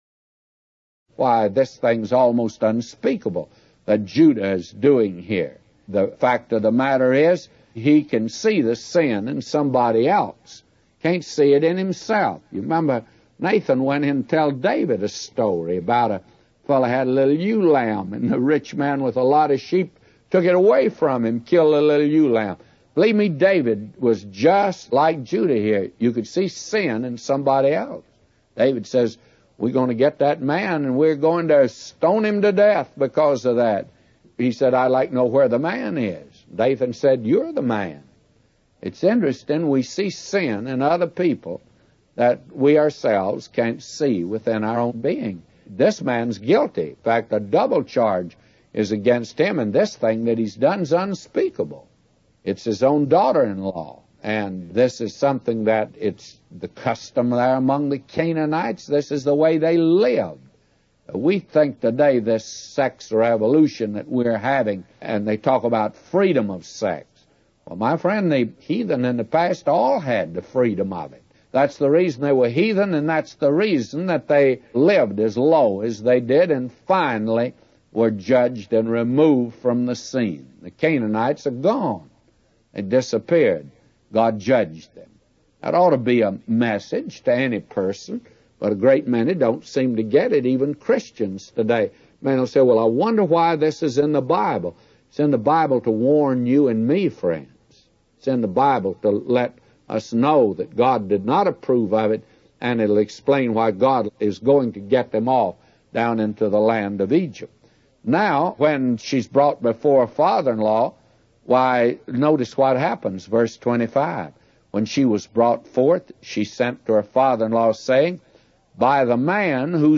A Commentary By J Vernon MCgee For Genesis 38:24-999